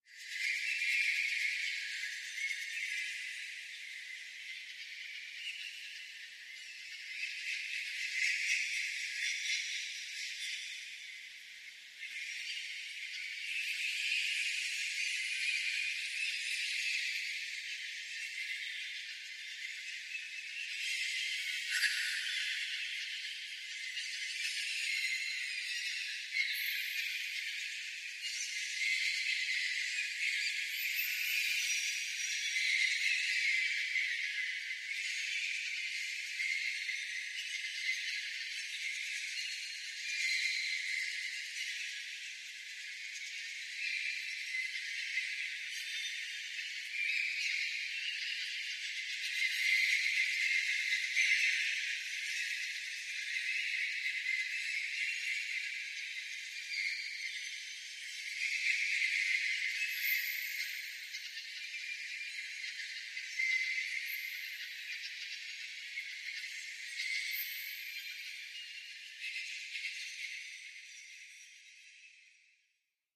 Bird Ambience Mystic Place Many Bird Layers